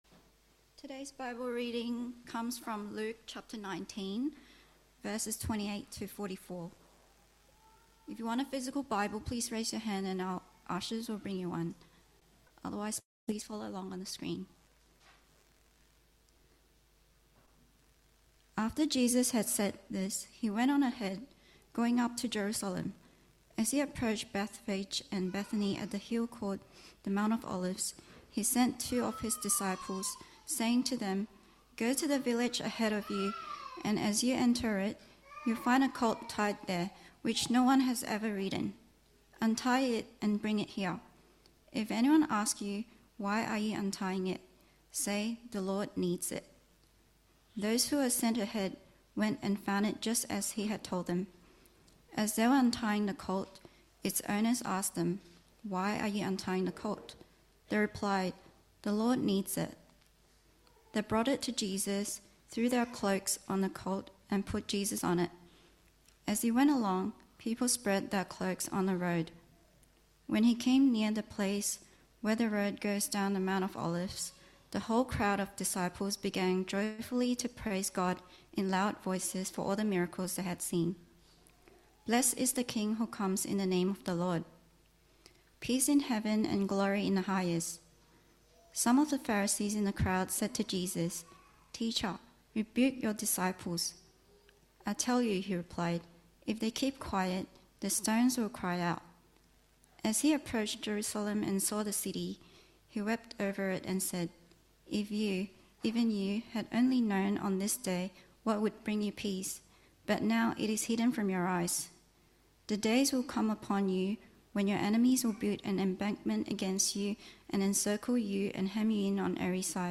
Service Type: 10:45 English